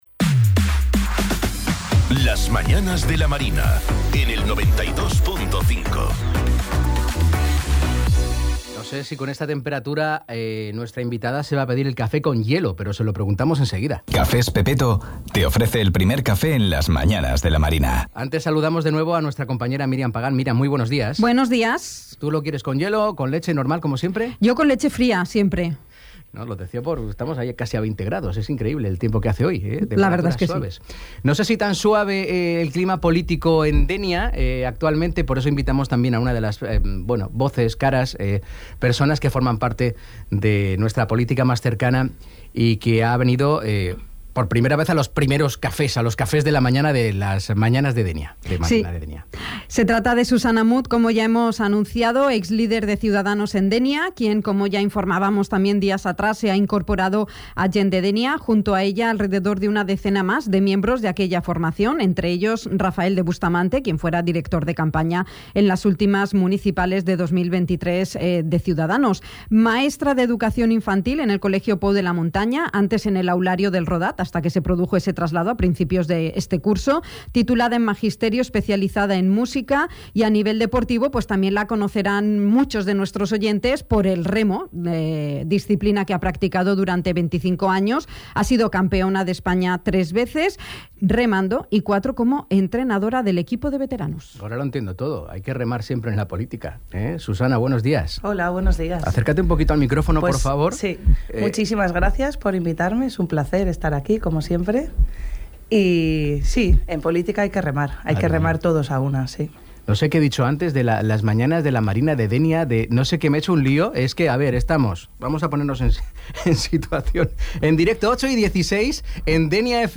En clave política, ha visitado el ‘primer café’ de Dénia FM, en este martes 16 de enero